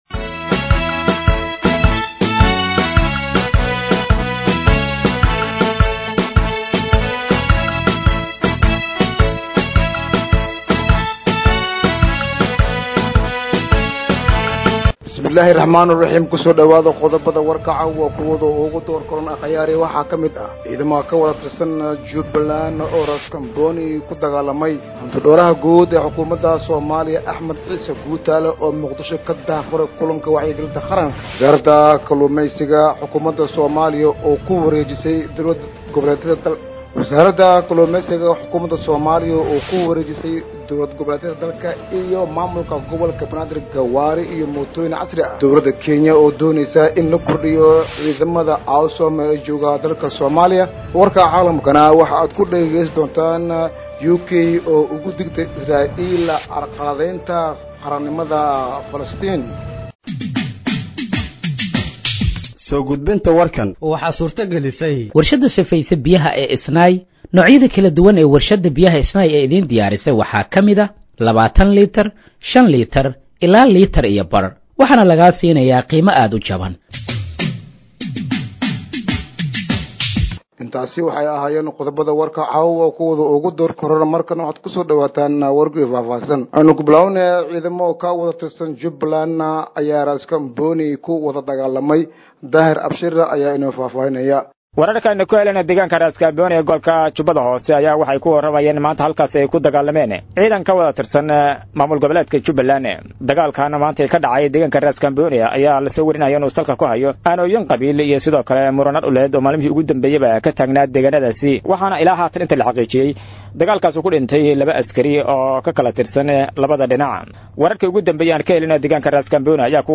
Dhageeyso Warka Habeenimo ee Radiojowhar 22/09/2025